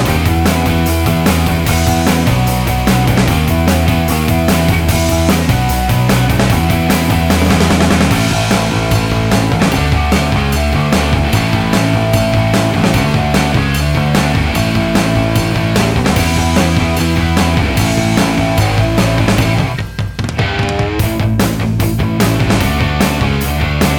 no Backing Vocals Punk 3:17 Buy £1.50